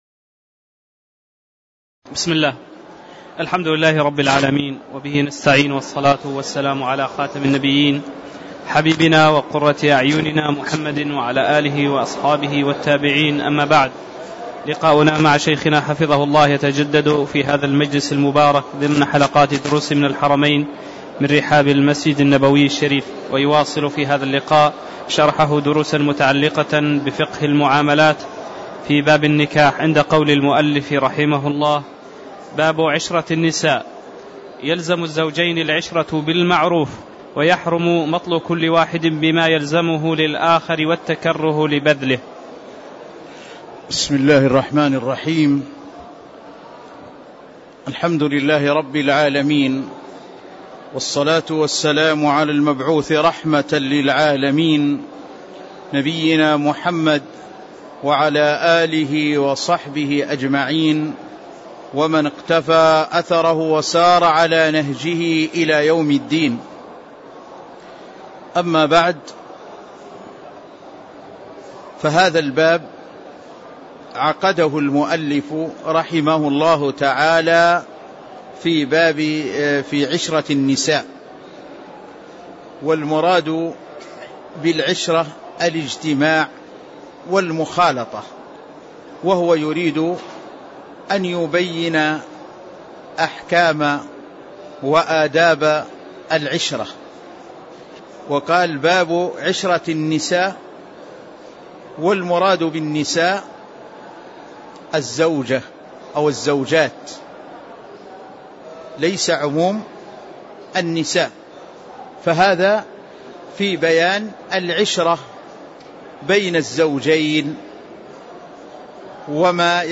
تاريخ النشر ٢٦ جمادى الأولى ١٤٣٧ هـ المكان: المسجد النبوي الشيخ